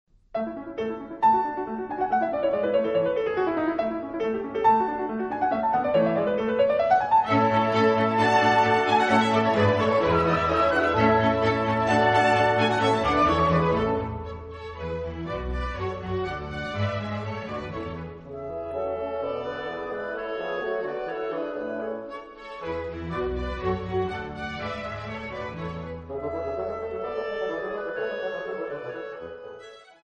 Mozart - Piano Concerto No. 23 in A major - III. Allegro assai